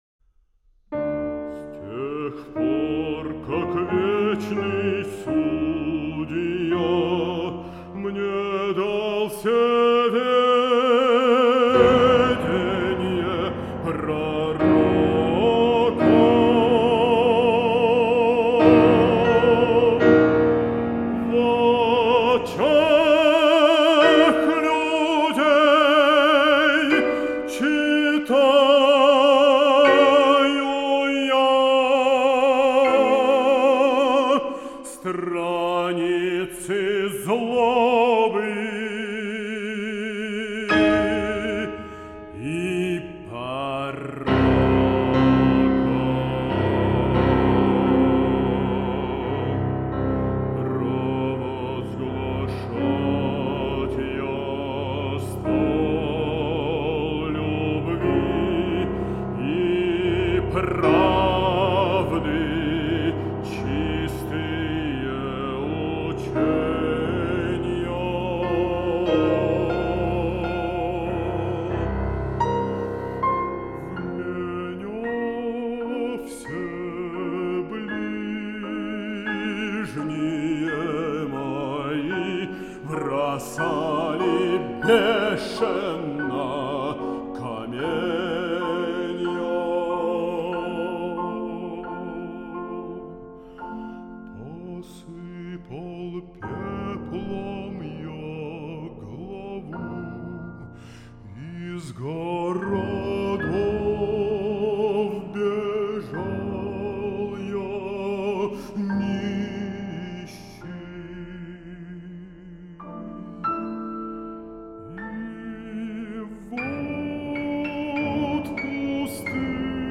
вокальный цикл для баритона и фортепиано